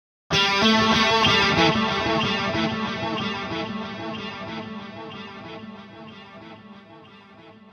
描述：空间蓝调
标签： 62 bpm Blues Loops Guitar Electric Loops 1.30 MB wav Key : Unknown
声道立体声